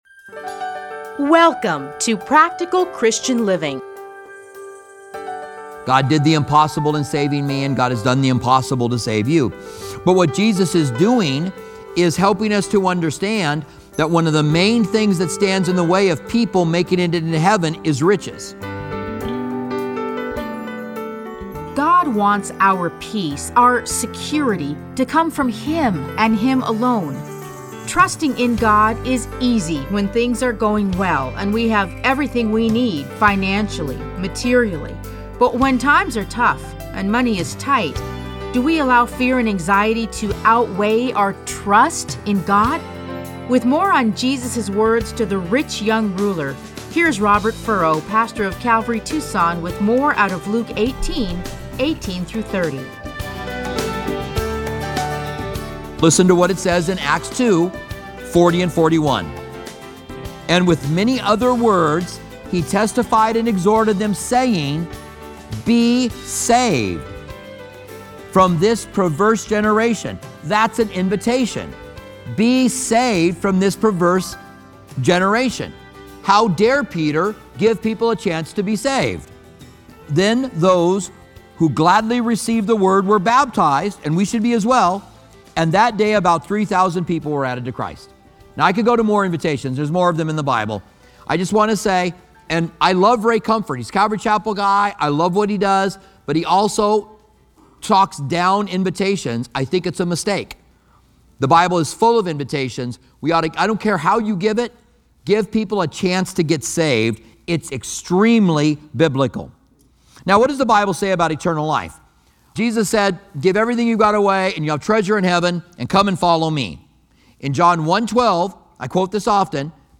Listen to a teaching from Luke 18:18-30.